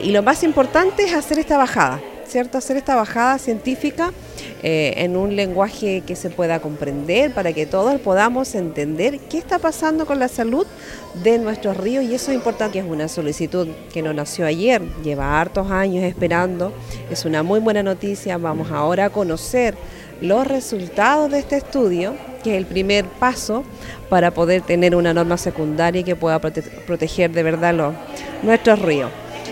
Cecilia Canales, Presidenta de la Comisión de Medioambiente del Concejo Municipal de Osorno destacó esta actividad donde junto a dirigentes sociales y alumnos, se pudo socializar de manera clara los alcances del estudio realizado por el Centro EULA en relación a los ríos.